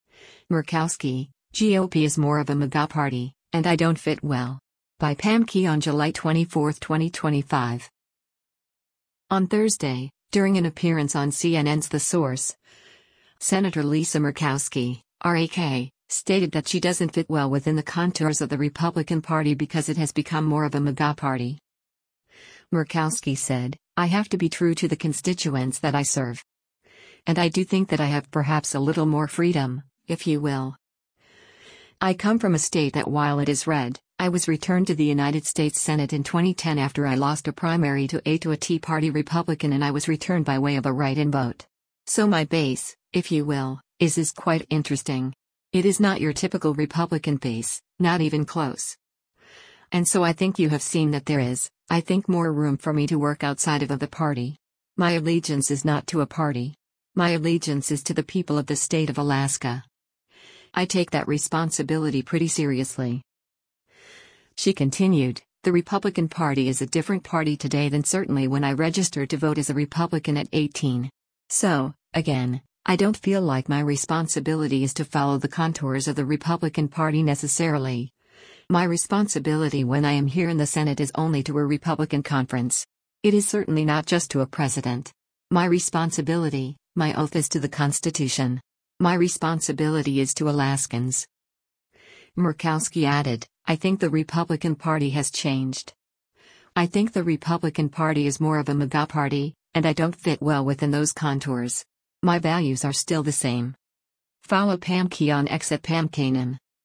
On Thursday, during an appearance on CNN’s “The Source,” Sen. Lisa Murkowski (R-AK) stated that she doesn’t fit well within the contours of the Republican Party because it has become “more of a MAGA party.”